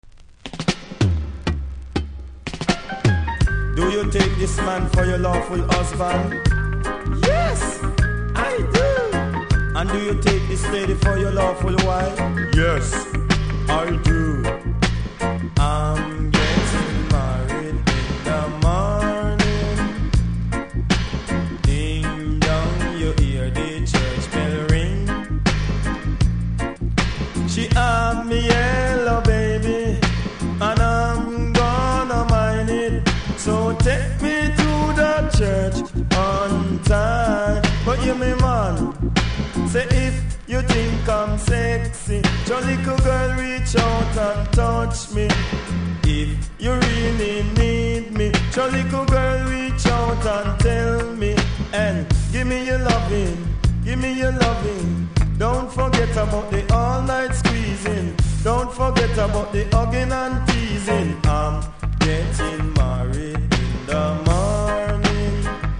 REGGAE 80'S
少しプレス起因のノイズあります。